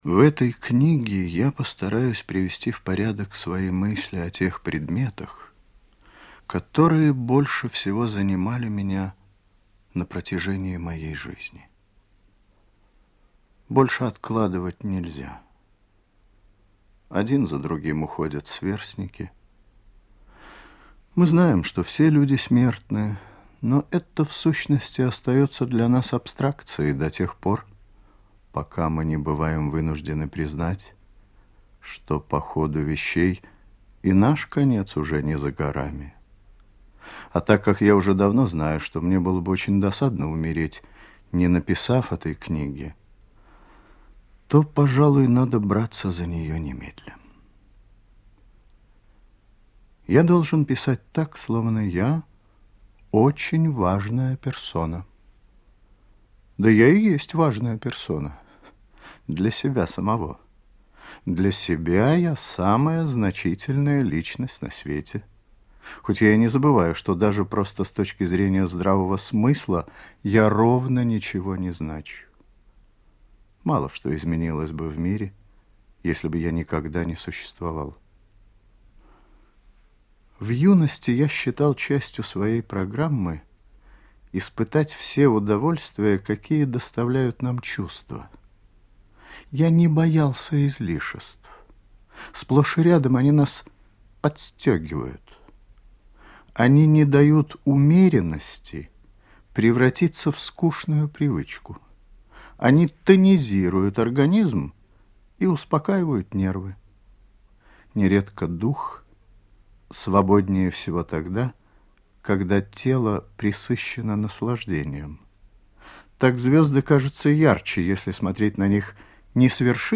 Виктор Татарский был не только радиоведущим, автором программ, но и мастером художественного слова, записал в своём исполнении большое количество художественных произведений.